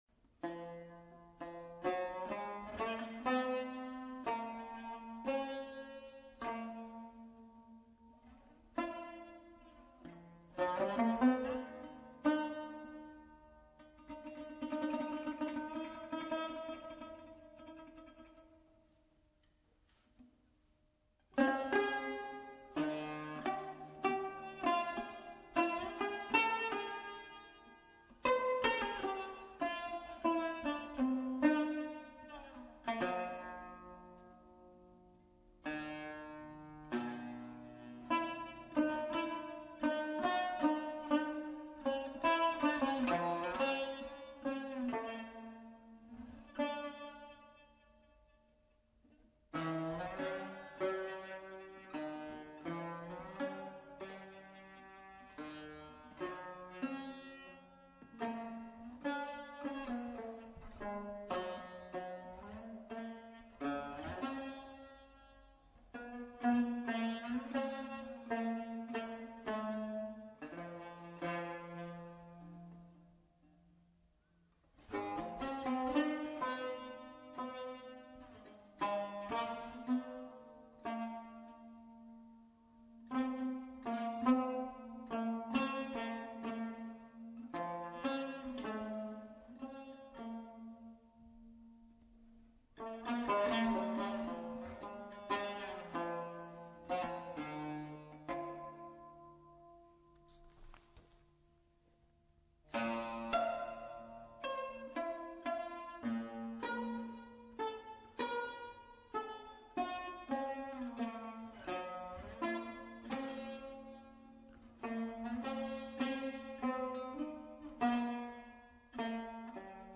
соло на тамбуре, инструмент турецкий